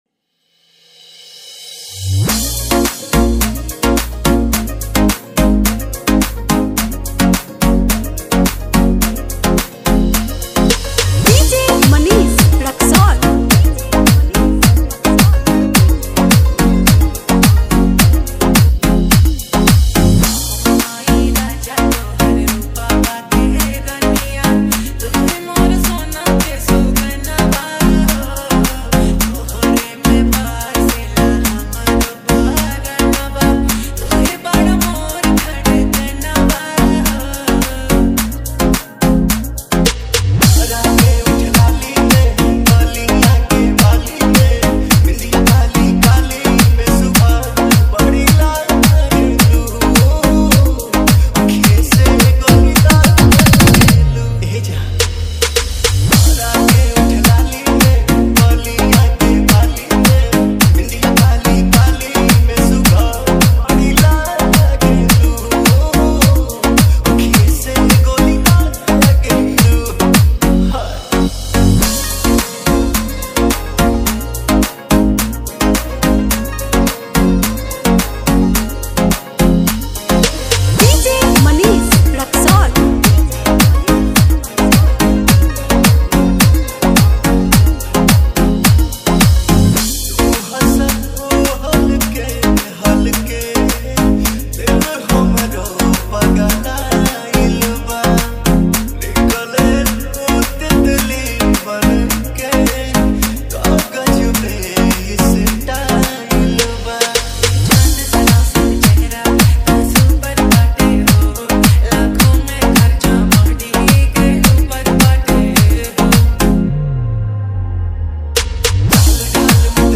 Bhojpuri Romantic DJ Remix